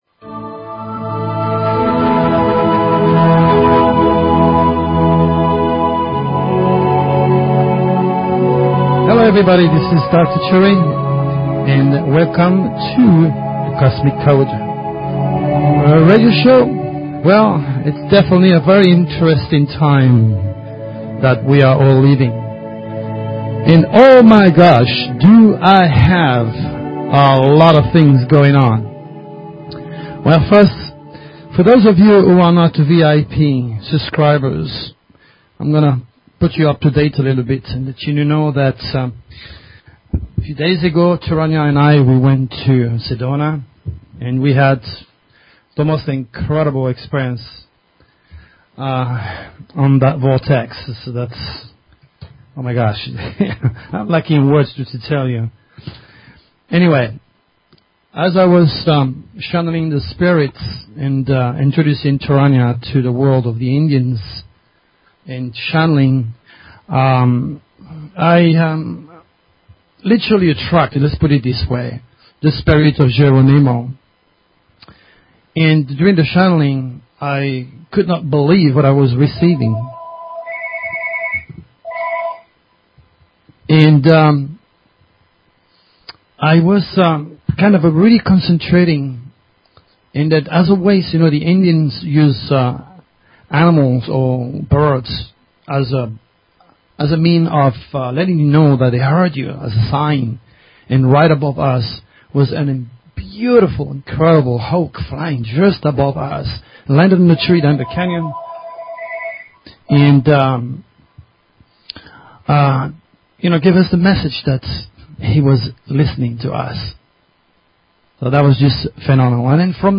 Talk Show Episode, Audio Podcast, The_Cosmic_Code and Courtesy of BBS Radio on , show guests , about , categorized as